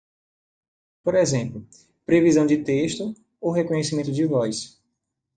Pronounced as (IPA)
/ʁe.kõ.ɲe.siˈmẽ.tu/